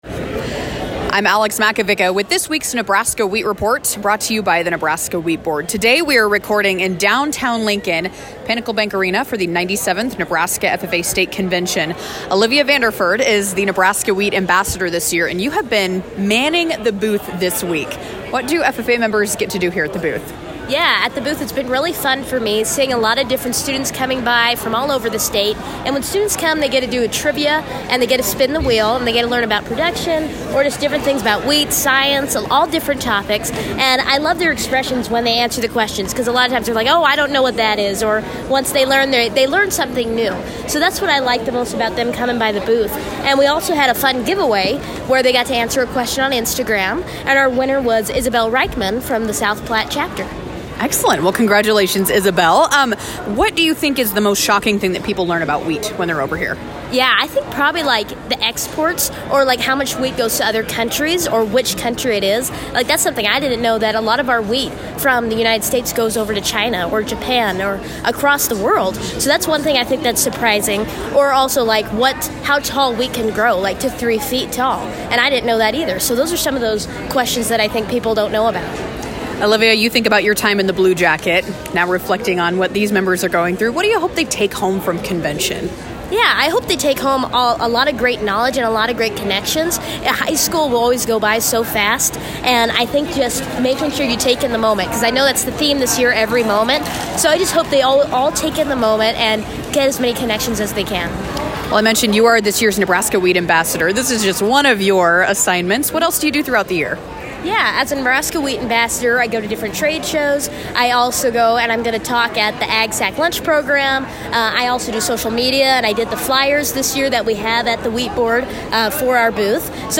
The Wheat Report are interviews conducted with farmers and wheat industry representatives regarding current events and issues pertaining to the Nebraska Wheat Board.